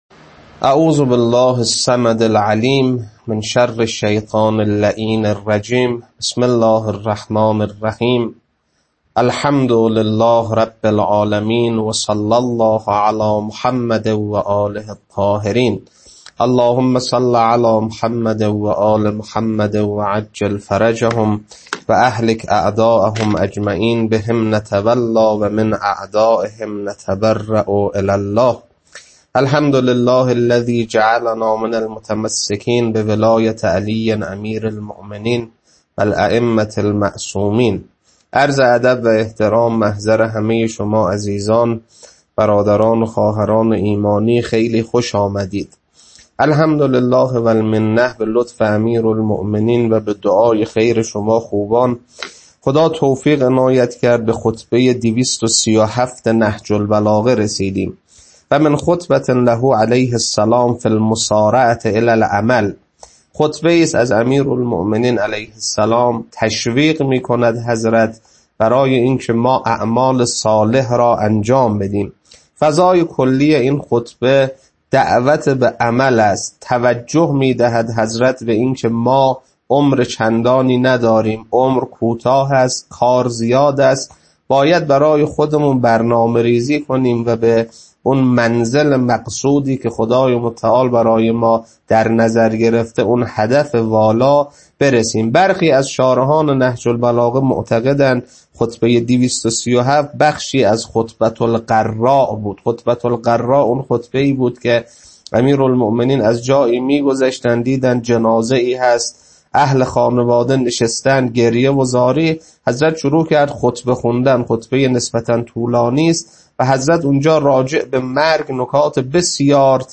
خطبه 237.mp3